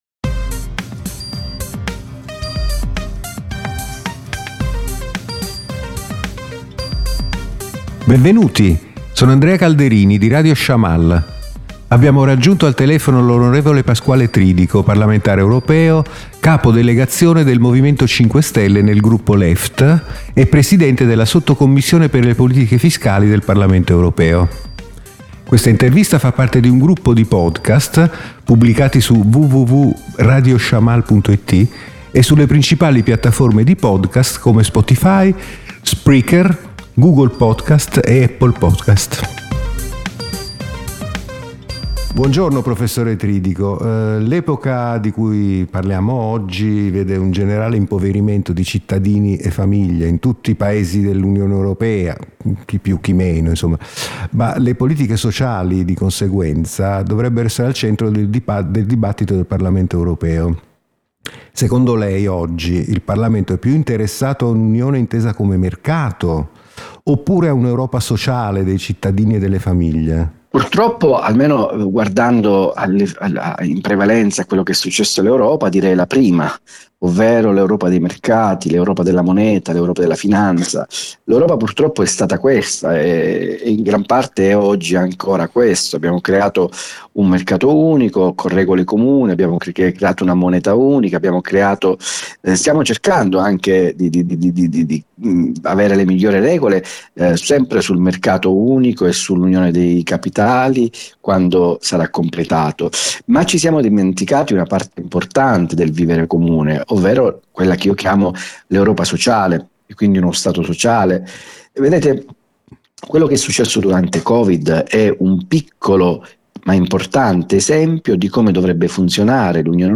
L’onorevole Pasquale Tridico, parlamentare europeo capo delegazione del Movimento 5 Stelle nel gruppo Left e presidente della sottocommissione per le politiche fiscali del Parlamento Europeo, intervistato